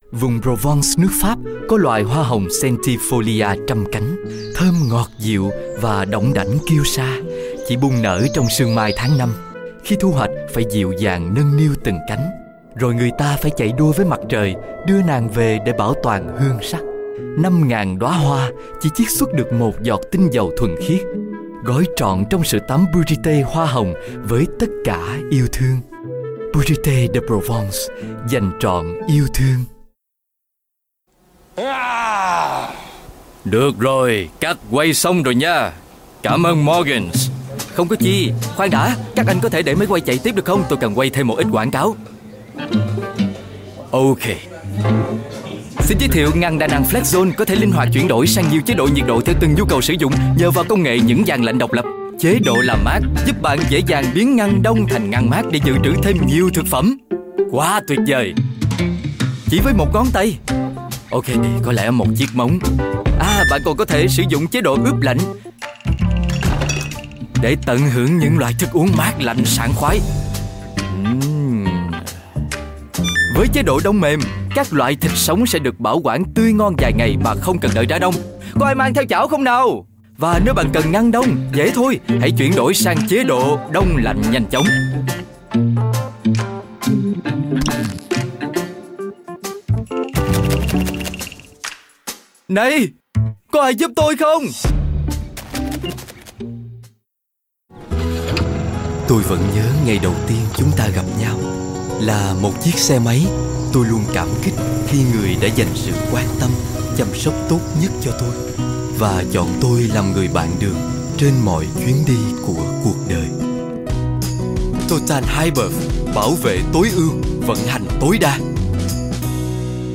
Voice Samples: Voice Sample 04
male